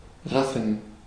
Ääntäminen
Vaihtoehtoiset kirjoitusmuodot (rikkinäinen englanti) 'og Synonyymit monopolize chopper shilling bogart tanner angel dust phencyclidine half-crown Ääntäminen US RP : IPA : /hɒɡ/ US : IPA : /hɑɡ/ IPA : /hɔːɡ/